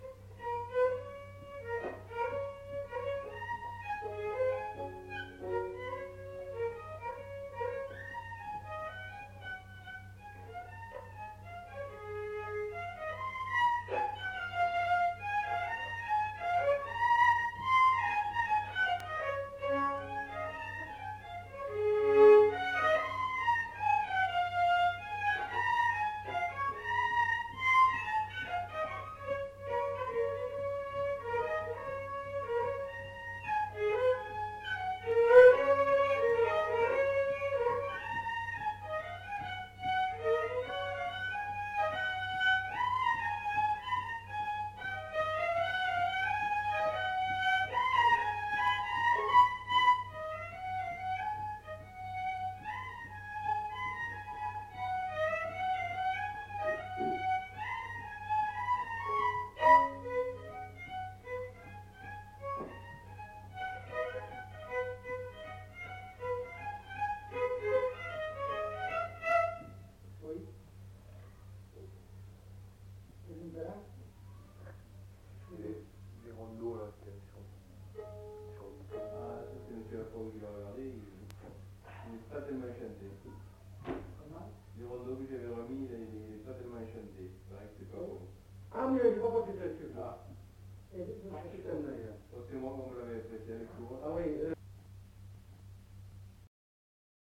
Mazurka
Aire culturelle : Lomagne
Département : Gers
Genre : morceau instrumental
Instrument de musique : violon
Danse : mazurka
Notes consultables : Discussion en fin d'item. Deux violons.